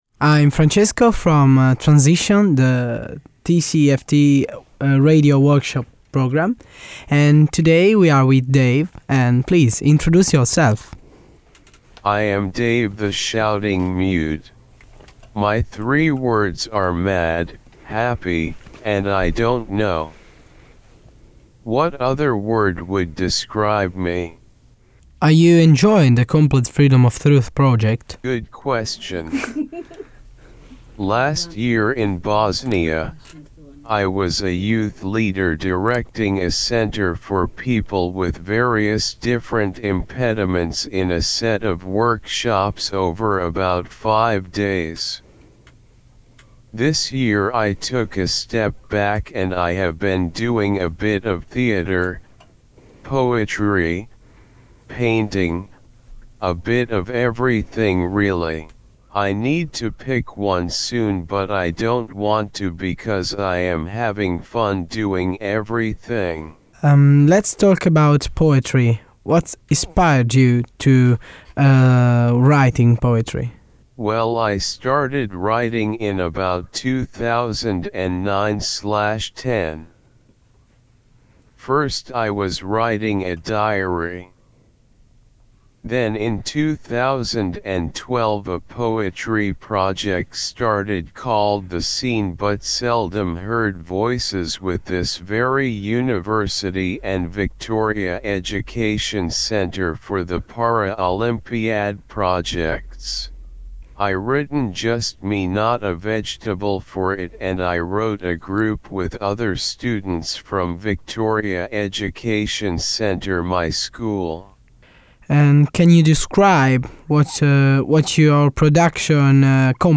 Transition radio programme recording Studio 1 BU